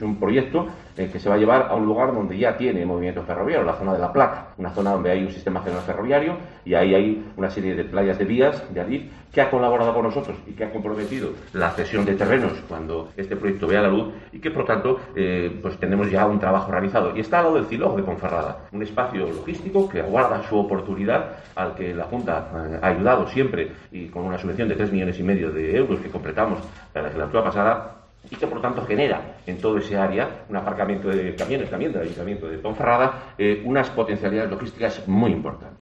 AUDIO: Escucha aquí a Juan Carlos Suárez Quiñones, consejero de Fomento y Medio Ambiente de la Junta de Castilla y León